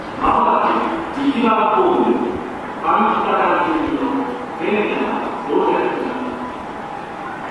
TM05 -- 接近放送 0:07 -- 上北台方面。東海道型放送の声です。